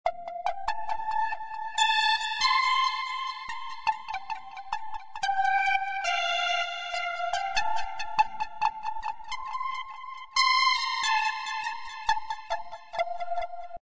恍惚的锯齿同步旋律
描述：Trancey Saw Sync Melody Trance Electro Electronic Synth
Tag: 140 bpm Trance Loops Synth Loops 2.31 MB wav Key : Unknown